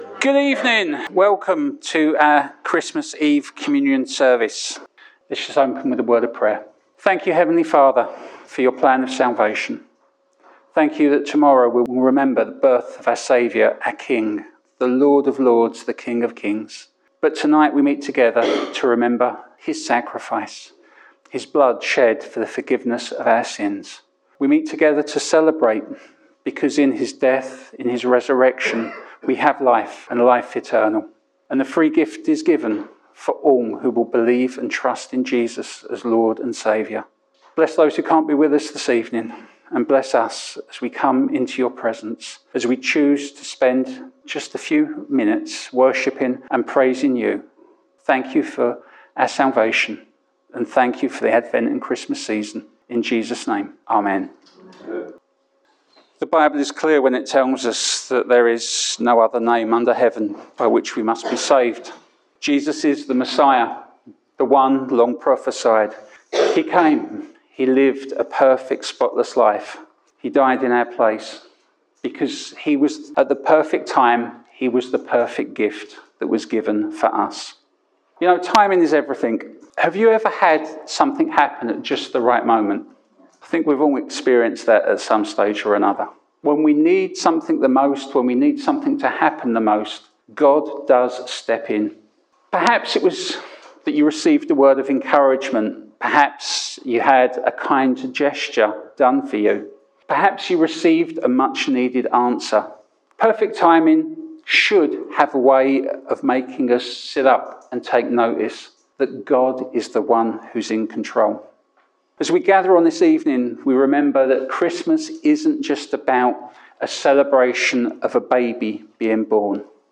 Viimeksi kuunneltu Christmas Day Online Sermon: Good News and the Wonder of the Incarnation [Series: Transformed by Truth].